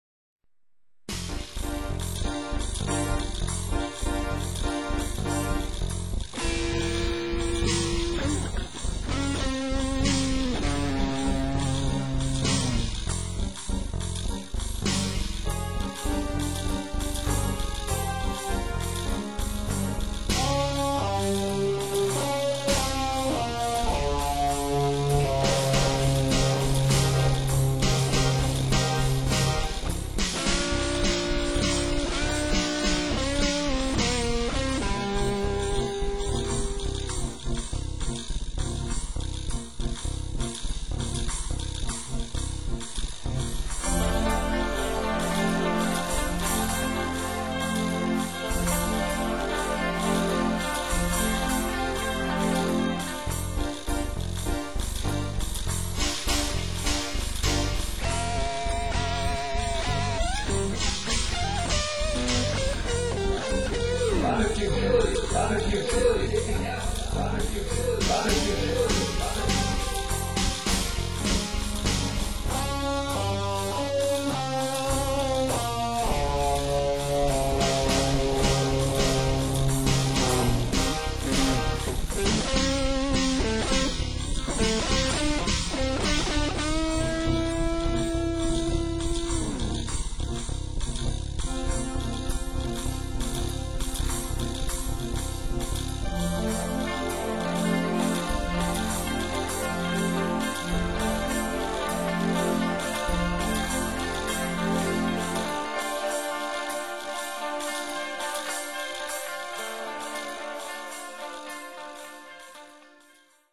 They are highly compressed from the originals, and converted to a suspect, but dead easy format - this is the reality of limited bandwidth.
A soundtrack for a little movie about my failings as a artist, executed as I learned how to navigate within Sonar.